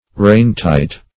Definition of rain-tight.
Search Result for " rain-tight" : The Collaborative International Dictionary of English v.0.48: Rain-tight \Rain"-tight`\ (r[=a]n"t[imac]t`), a. So tight as to exclude rain; as, a rain-tight roof.